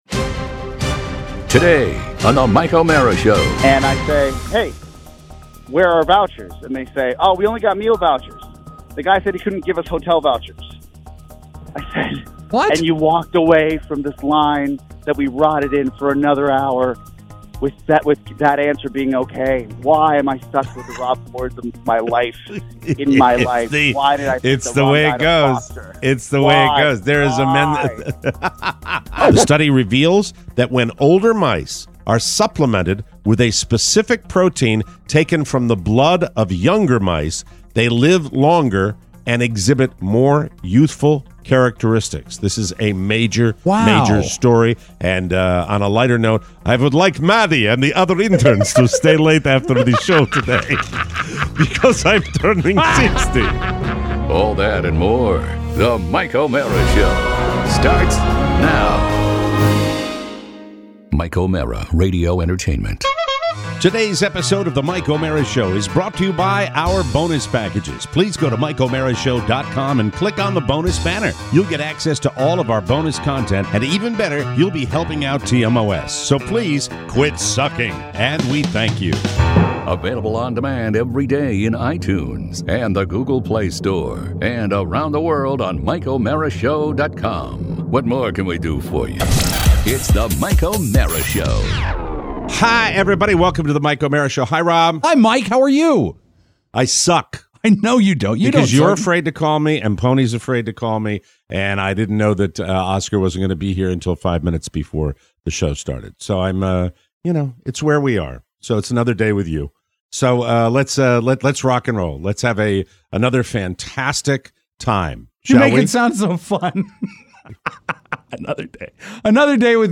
on the phone! Plus, ginormous bugs… flat cookies… mysterious dialing… and your letters.